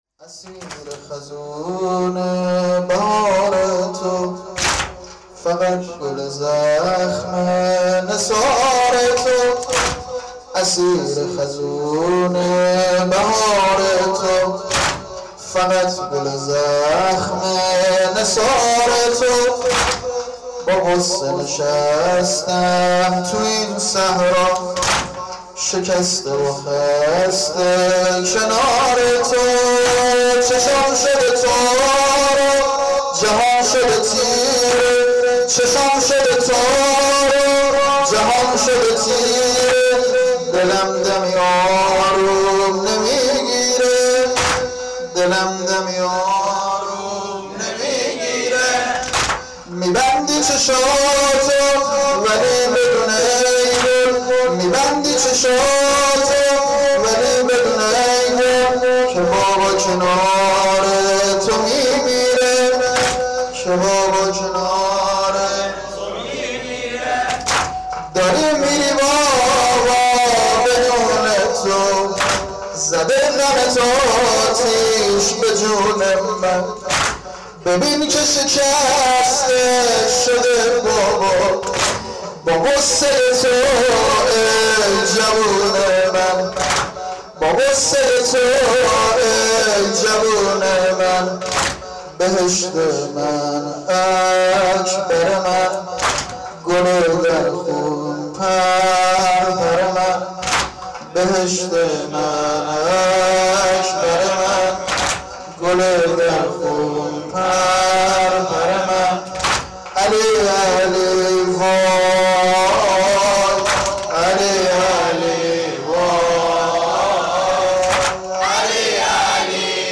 • مراسم سینه زنی شب هشتم محرم هیئت روضه الحسین
واحد-اسیر-خزونه-بهار-تو.mp3